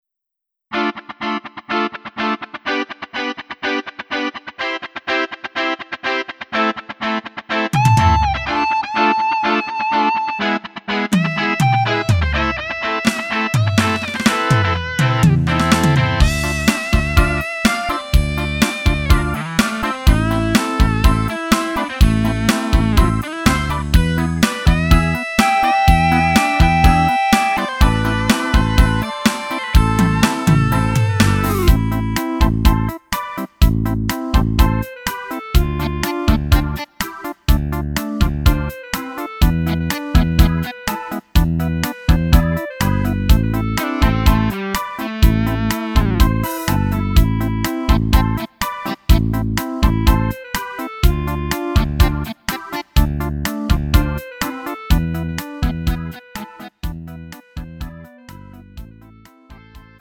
음정 원키 4:21
장르 가요 구분 Lite MR